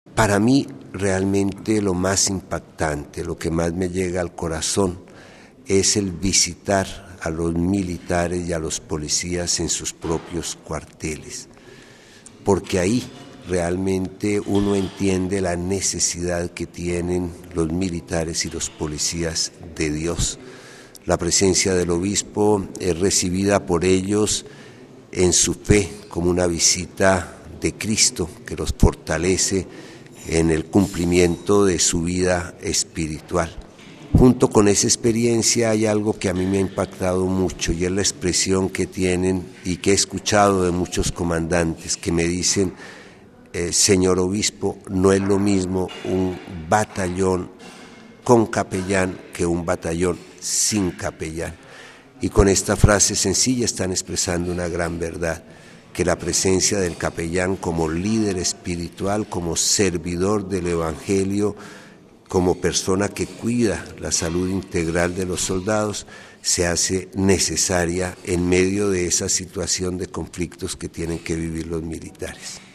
Entrevista al obispo castrense de Colombia, monseñor Suescun